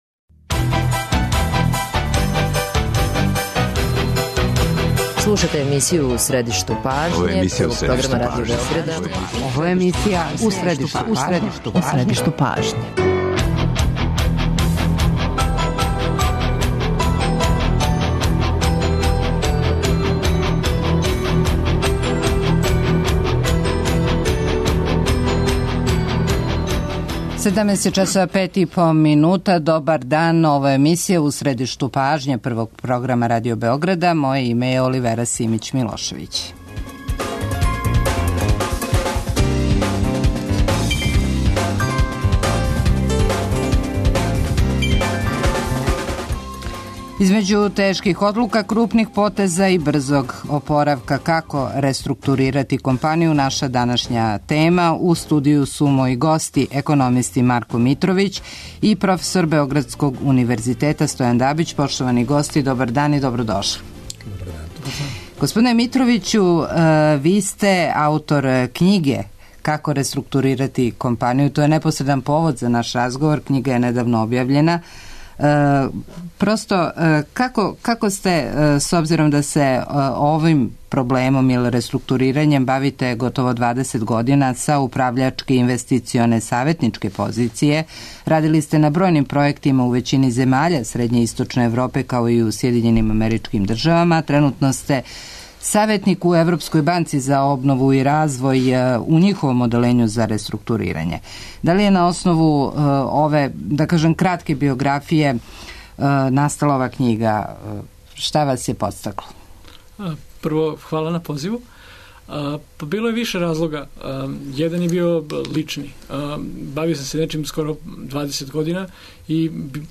Гости емисије су економисти